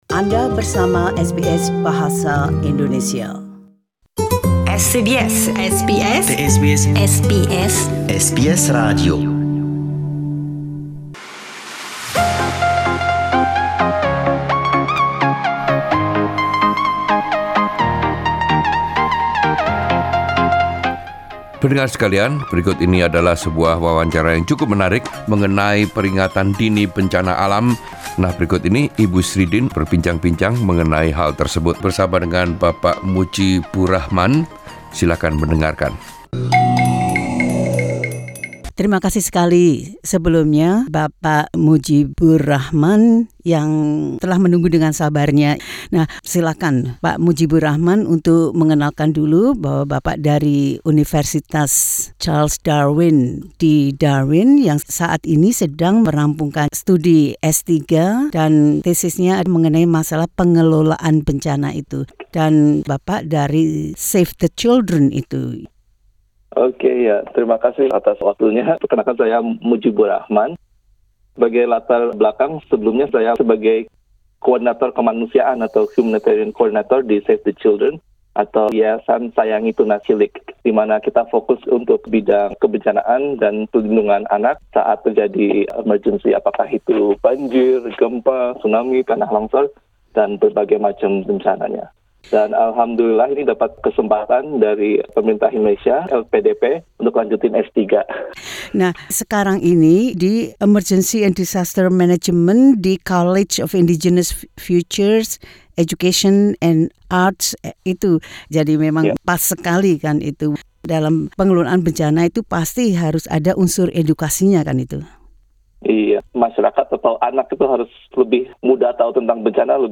SBS Bahasa Indonesia